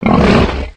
boar_threaten_2.ogg